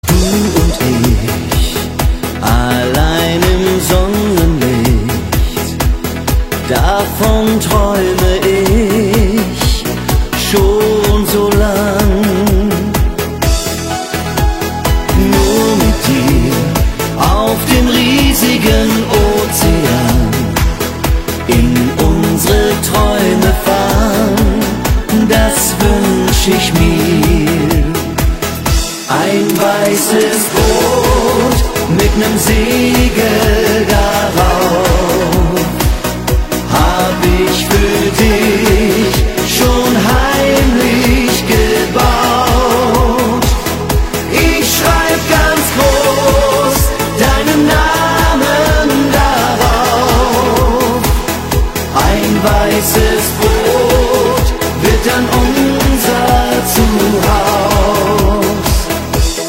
Kategorien: Musik